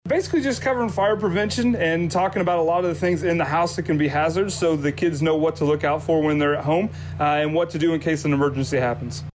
Firefighter